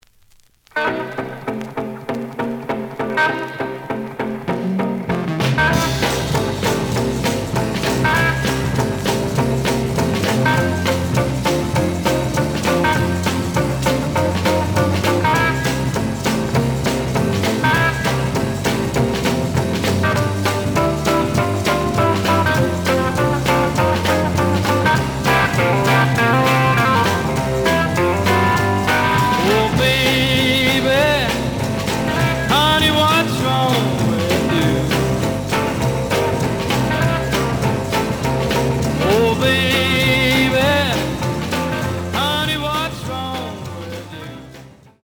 The audio sample is recorded from the actual item.
●Genre: Rhythm And Blues / Rock 'n' Roll
Slight damage on both side labels. Plays good.)